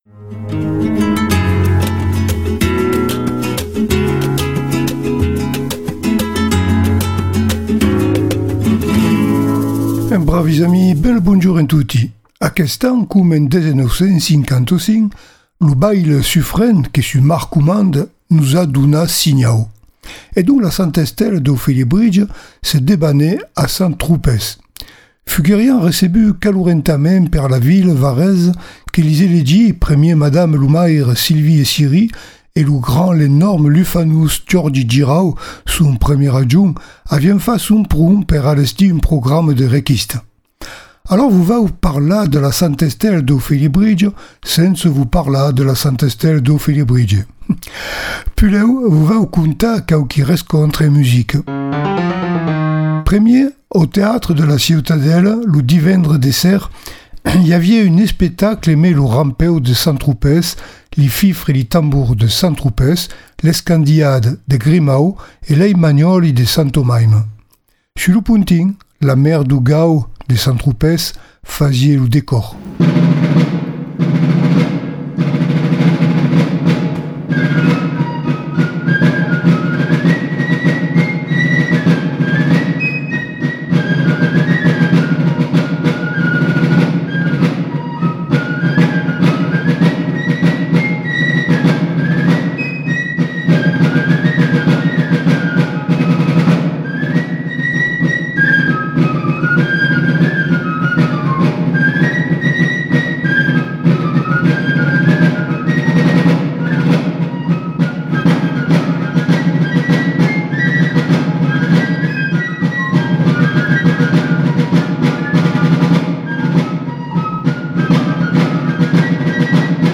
Votre chronique en Occitan